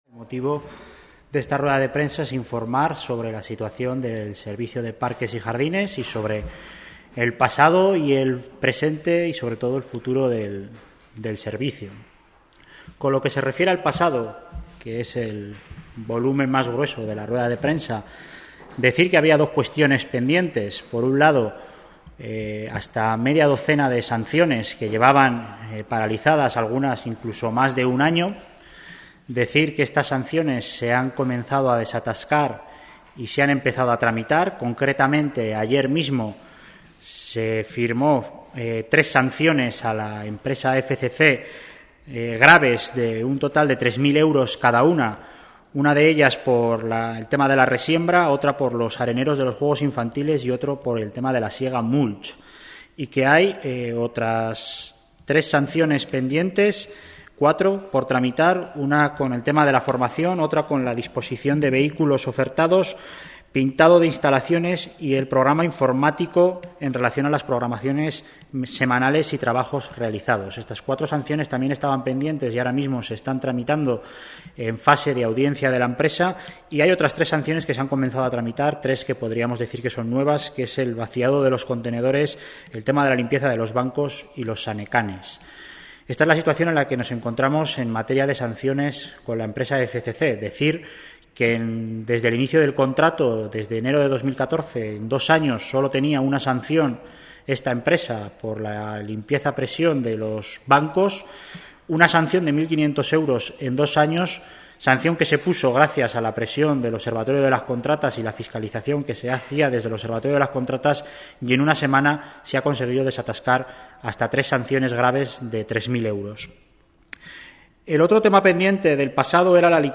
El consejero de Servicios Públicos y Personal, Alberto Cubero, ha destacado hoy en rueda de Prensa que el dato de tres sanciones impuestas en estos meses contrasta con un único expediente tramitado desde que inició este contrato, hace dos años.